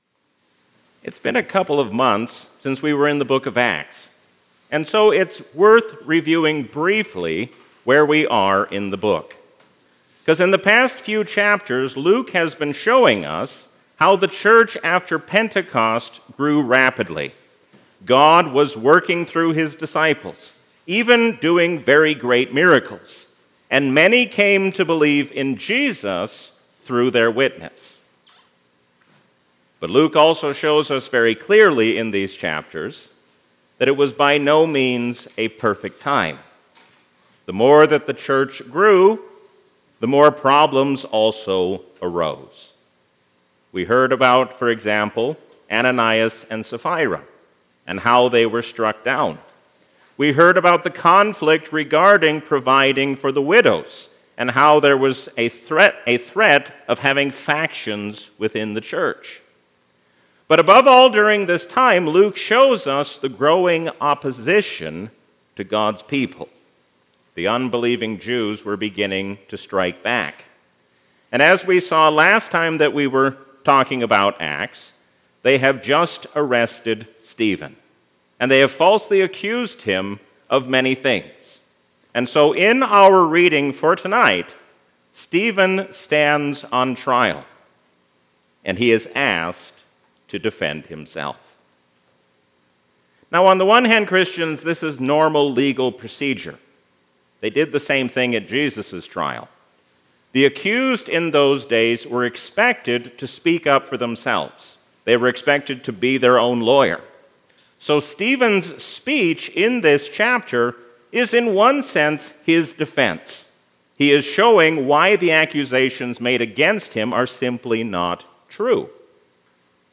A sermon from the season "Epiphany 2025." Stephen shows us what it means to be like Jesus even in a difficult hour.